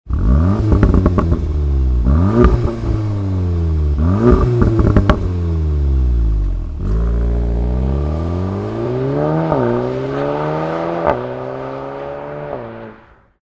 AXLE-BACK-SYSTEM
Audi_S3_Facelift_REMUS_Axle_Back_System.mp3